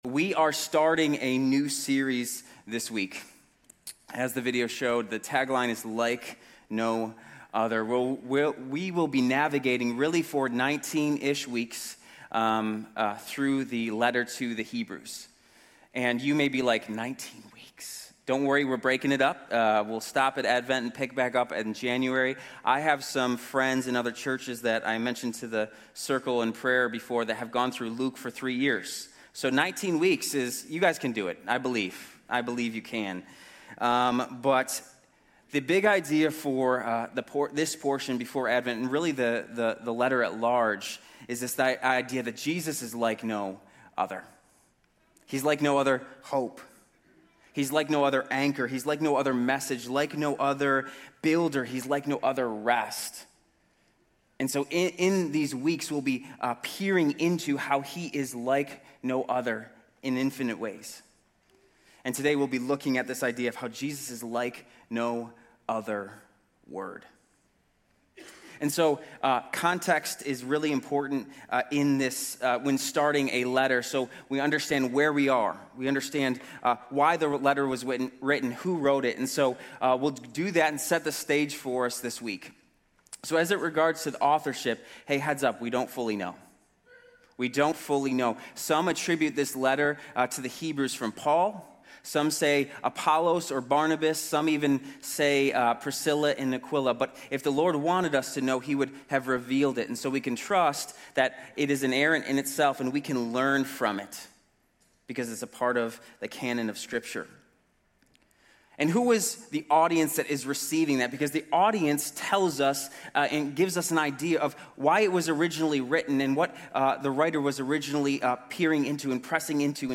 Grace Community Church University Blvd Campus Sermons 9_14 University Blvd Campus Sep 15 2025 | 00:35:02 1x 00:00 / 00:35:02 Subscribe Share RSS Feed Share Link Embed